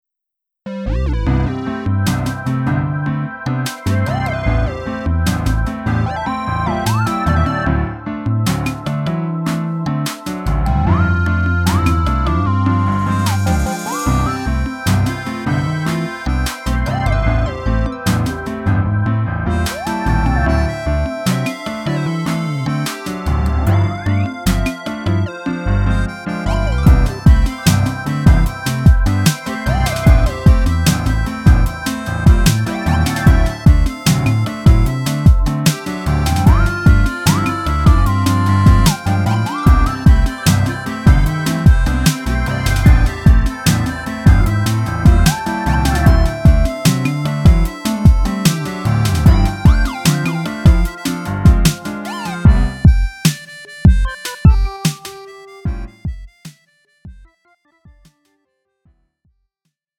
음정 원키 3:26
장르 가요 구분 Lite MR
Lite MR은 저렴한 가격에 간단한 연습이나 취미용으로 활용할 수 있는 가벼운 반주입니다.